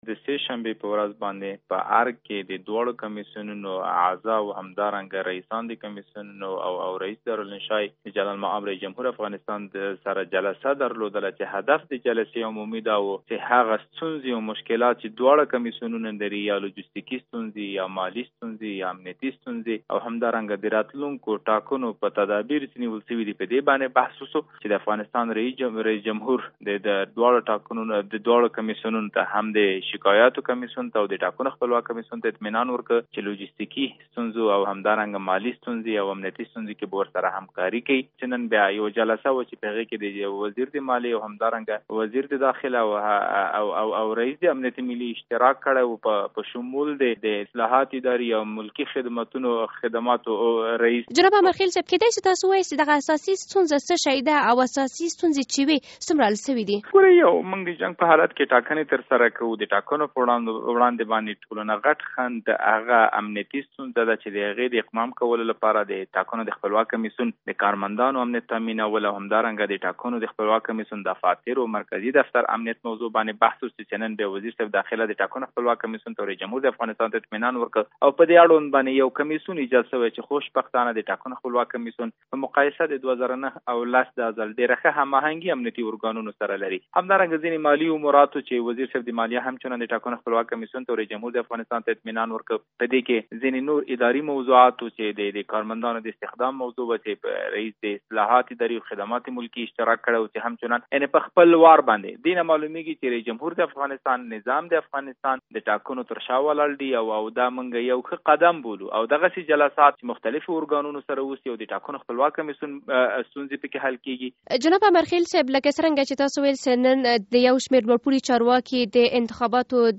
د انتخاباتو د خپلواک کمېسیون د دارالانشا له مشر ضیاالحق امرخیل سره مرکه